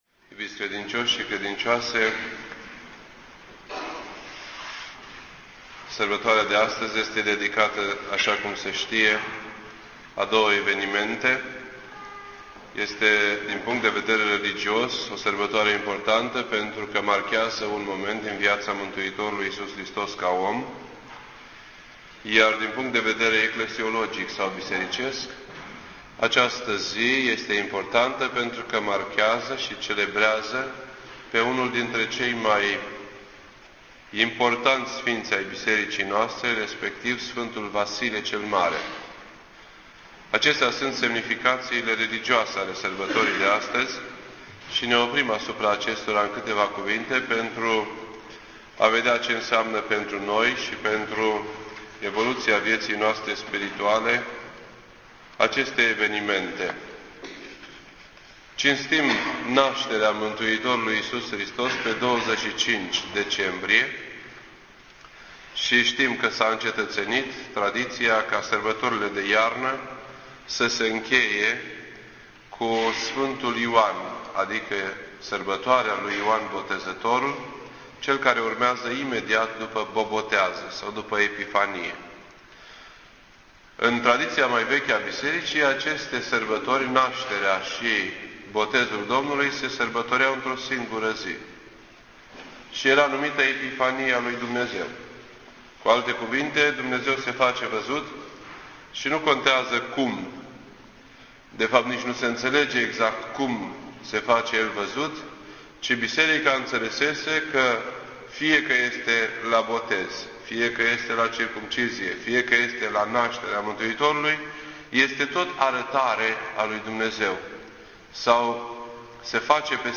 This entry was posted on Thursday, January 1st, 2009 at 7:14 PM and is filed under Predici ortodoxe in format audio.